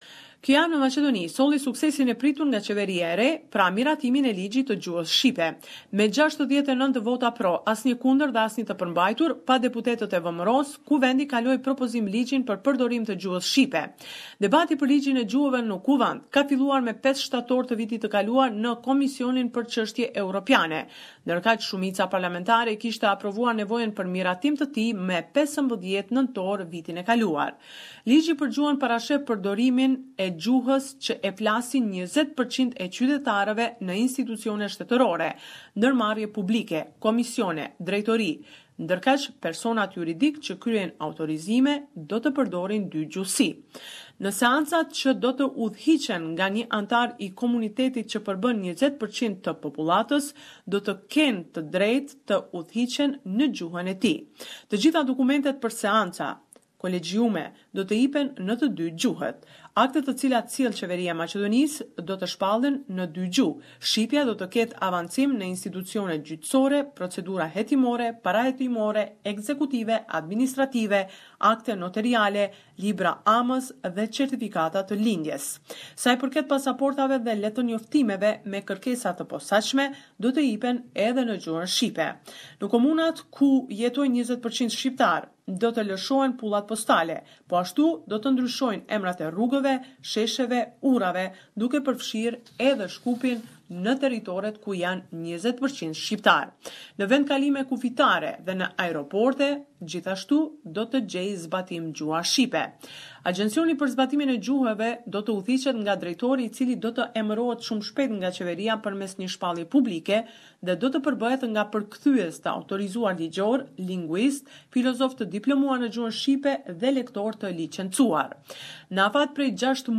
The weekly report with the latest developments in Macedonia